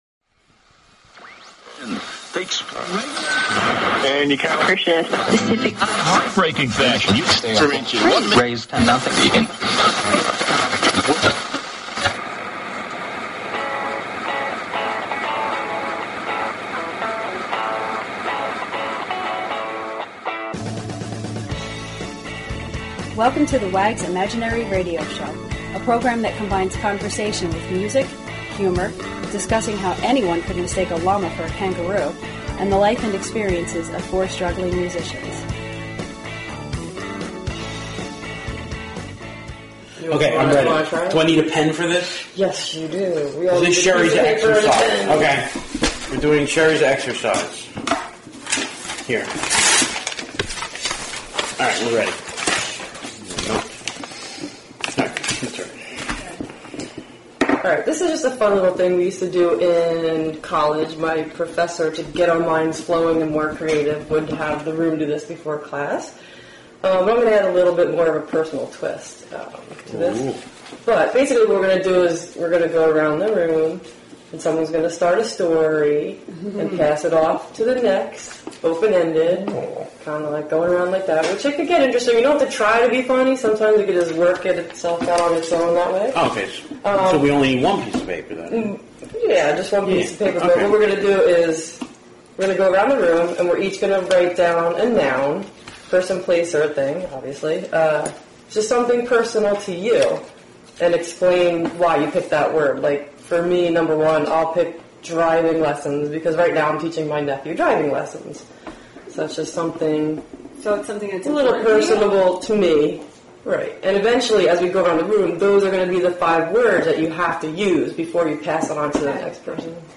Talk Show Episode, Audio Podcast, The_Wags_Imaginary_Radio_Show and Courtesy of BBS Radio on , show guests , about , categorized as
It is a podcast that combines conversation with music, humor & the life experiences of four struggling musicians from New Jersey.